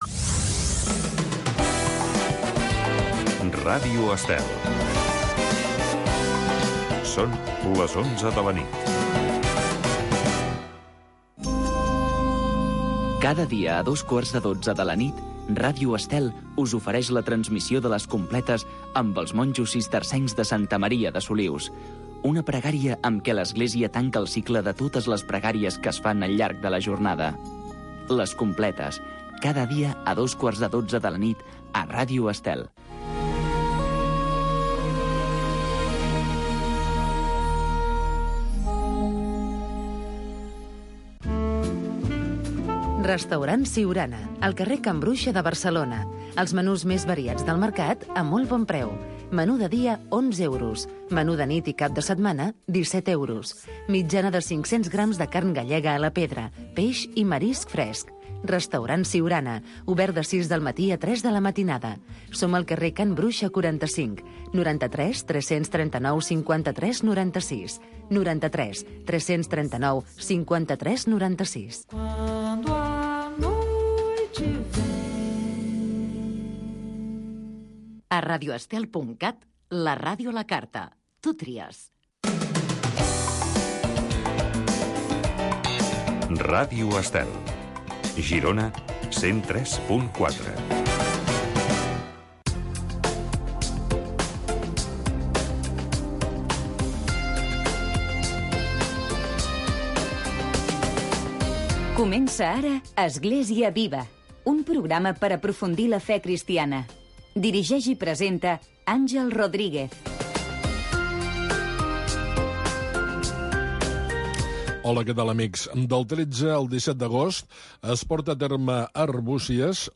Església viva. Magazine d’actualitat cristiana del bisbat de Girona.